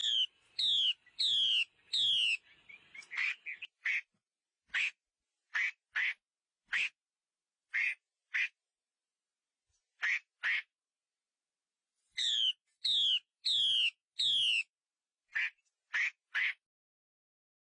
画眉母鸟叫声
画眉，也叫画眉鸟、中国画眉，属于噪鹛科，体重54-54克，体长21-21厘米。中型鸣禽。